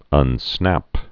(ŭn-snăp)